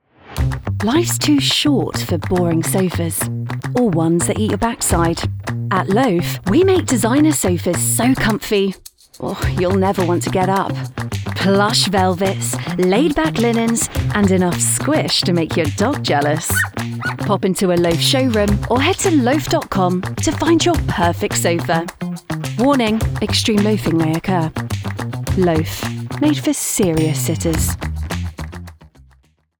Native Voice-Samples
With over 7 years experience as a voiceover I have had the pleasure of voicing for multiple brands recording from my broadcast quality home studio or in-person sessions.
Junger Erwachsener
Mezzosopran